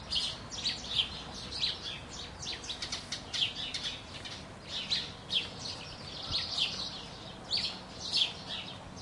各类鸟类
描述：鸟儿快乐地鸣叫（或愤怒地鸣叫？）用Zoom H4N录制，用Adobe Audition编辑。
标签： 鸟类 各种鸟
声道立体声